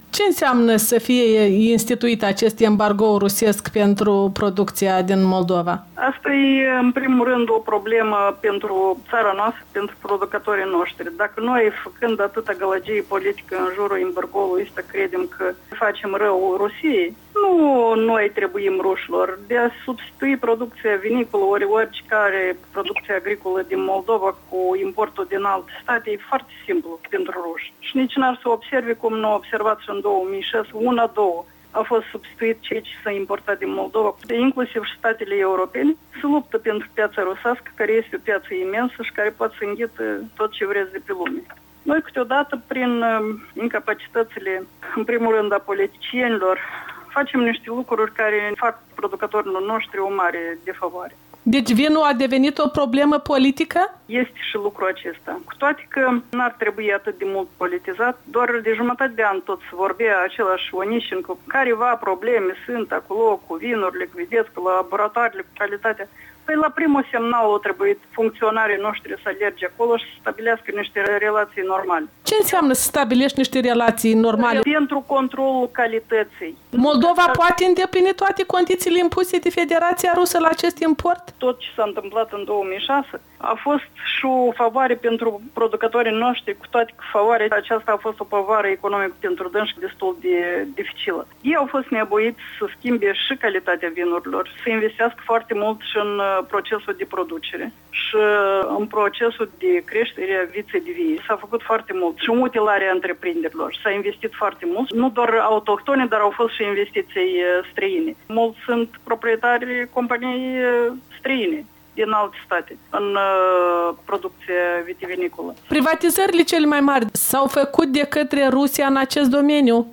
Fostul prim-ministru Zinaida Greceanîi în dialog